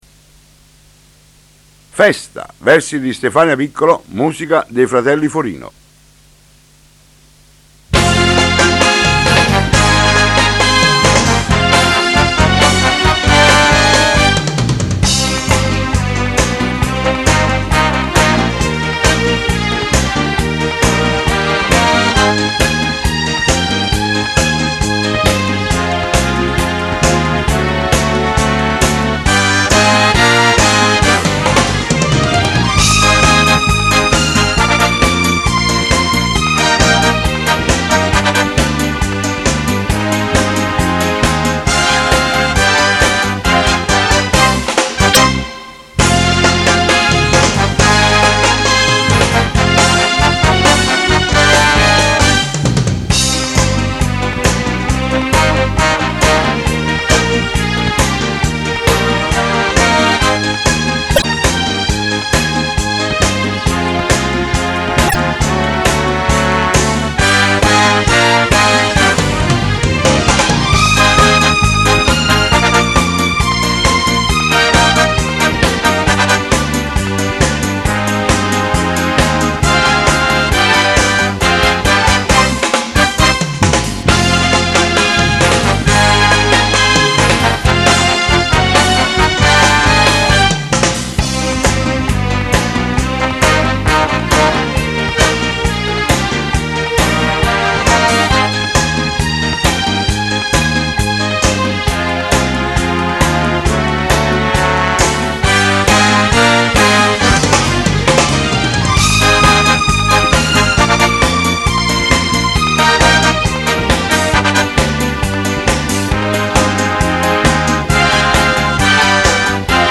versione strumentale